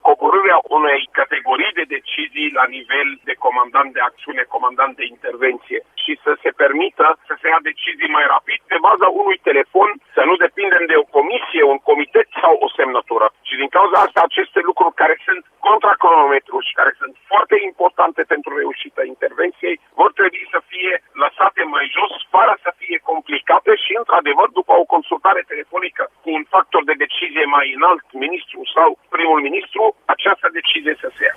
Şeful Departamentului pentru Situaţii de Urgenţă, Raed Arafat, a declarat, astăzi, la Apelul Matinal de la Radio România Actualităţi, că un bilanţ al exerciţiului SEISM 2018 va fi făcut în curând, după ce vor fi transmise toate rapoartele de la instituţiile participante.